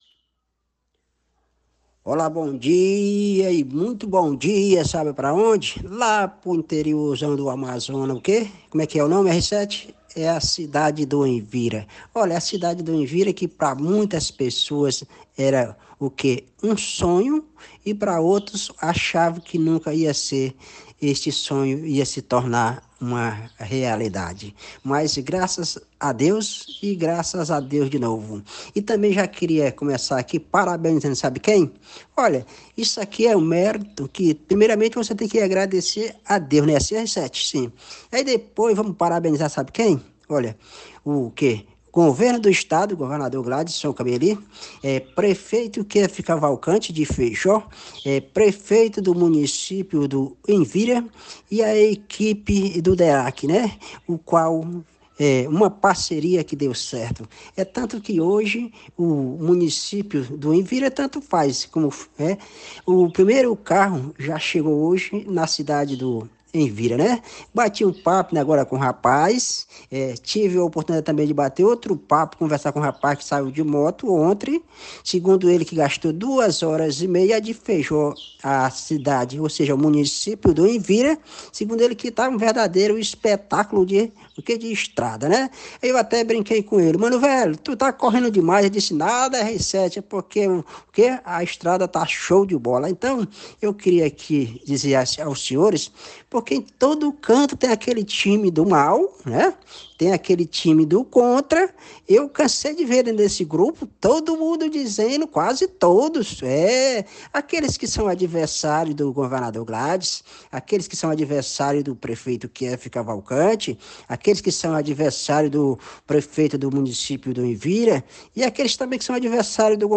Veja o áudio do vídeo-reporter